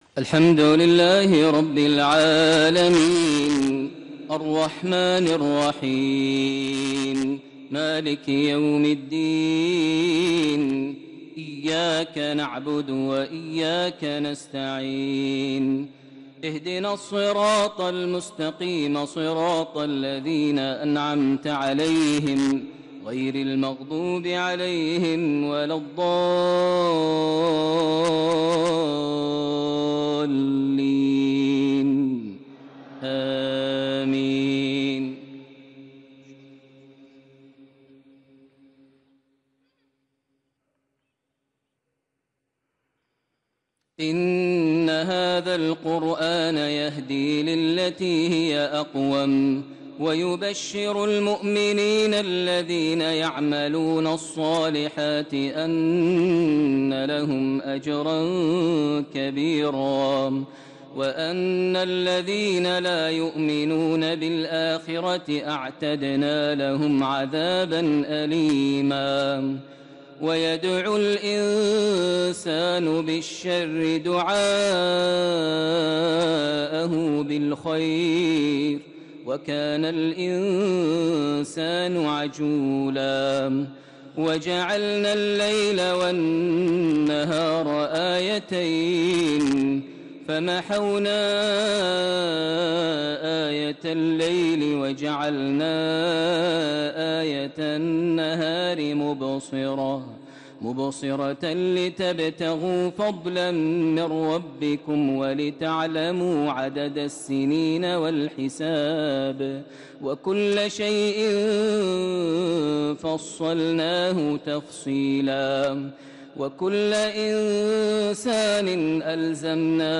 صلاة العشاء الإثنين ٢ صفر ١٤٣٦هـ سورة الإسراء ٩-١٧ + خواتيم سورة الحشر > زيارة قصيرة للشيخ ماهر المعيقلي لمنطقة بريدة ١٤٣٦هـ > المزيد - تلاوات ماهر المعيقلي